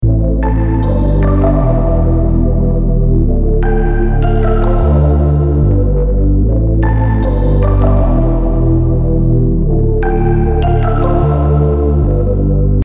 FUTURE BASS SAMPLE PACK
16 Amin – Starter Group
Antidote-Limitless-16-Amin-Starter-Group.mp3